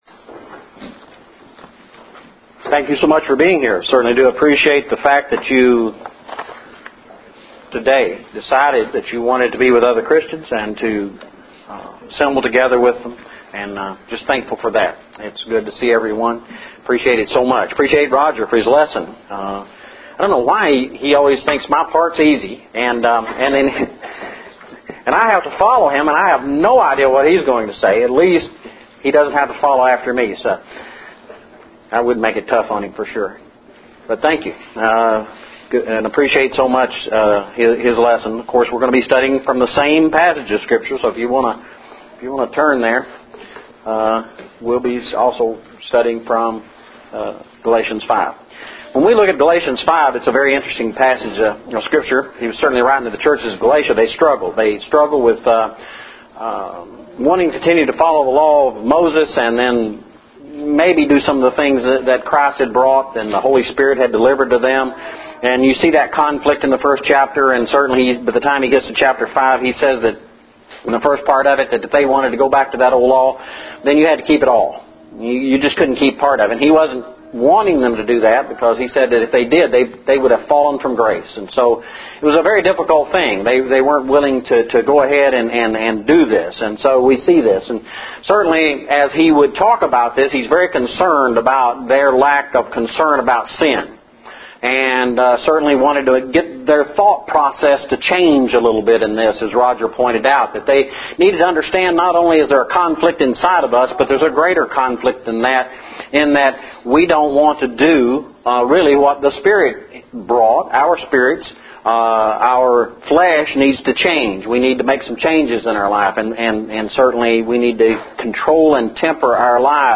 Walking in the Spirit Lesson – 11/20/11